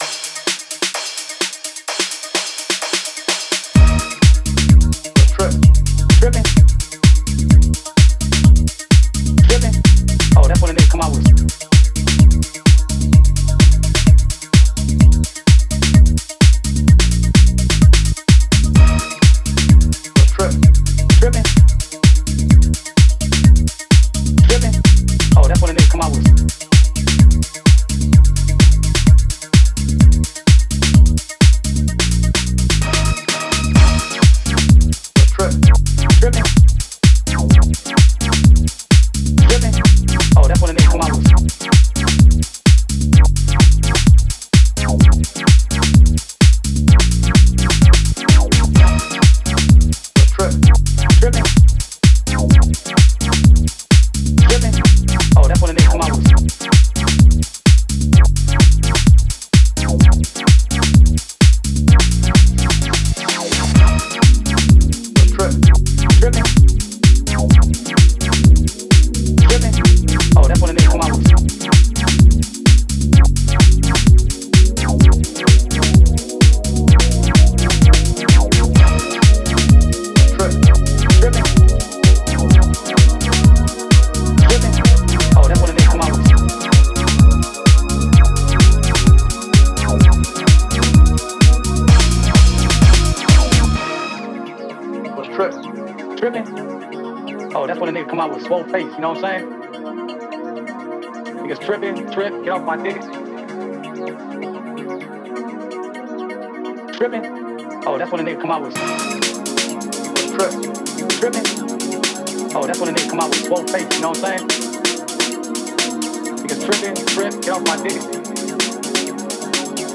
ファンキーにスウィングし艶やかなリフやスタブで小気味良く煽る